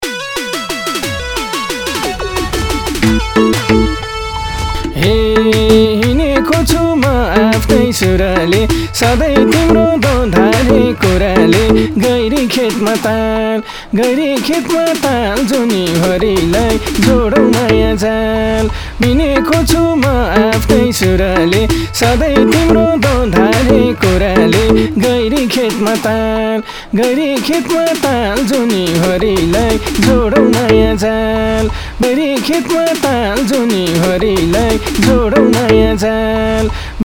SOLO SINGING TUNE TRACKS (एकल गायन तर्फ) Singing View